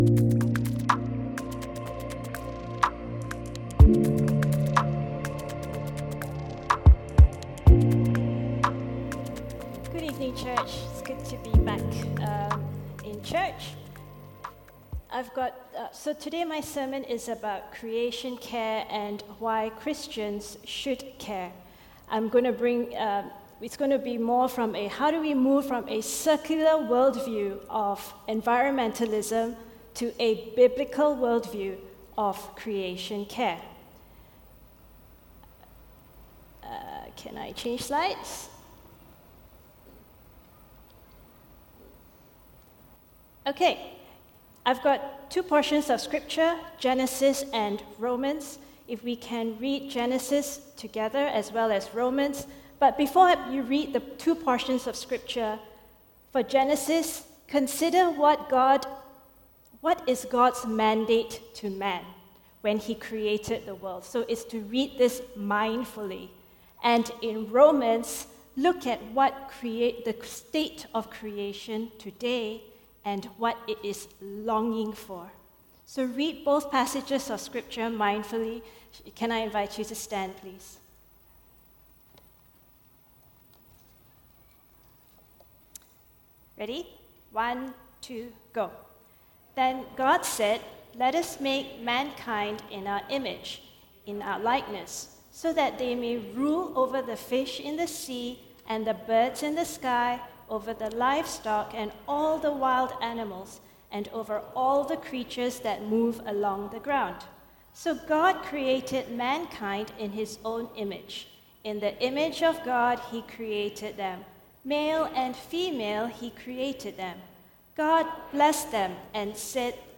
All Sermons Biblical Worldview of Creation Care July 30